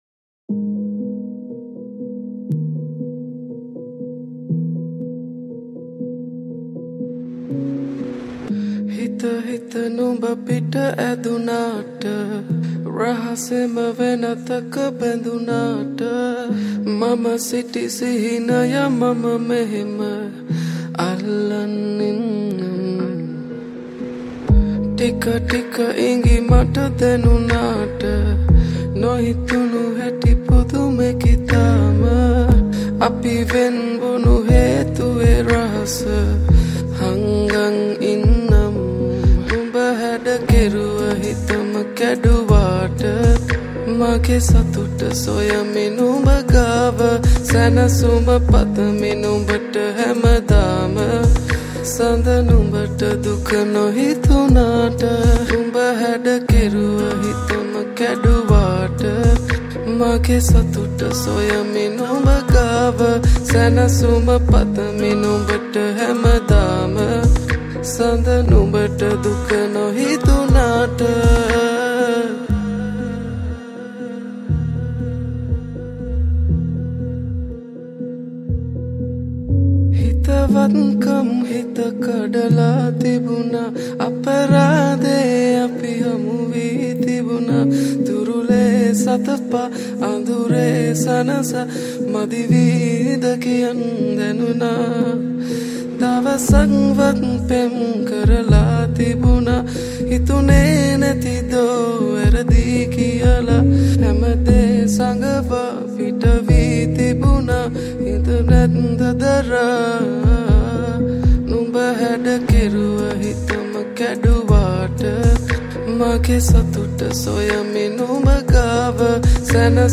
Vocals
Bass